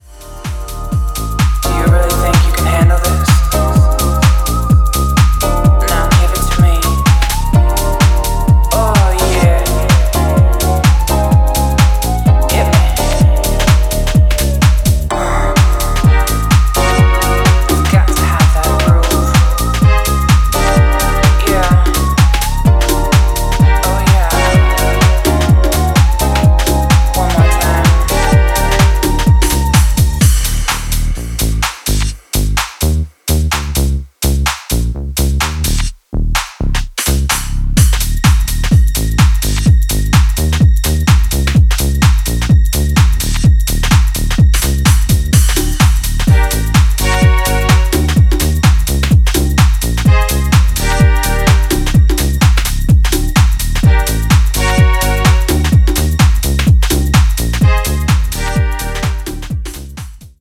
NYガラージ・ハウスに通じるパワフルな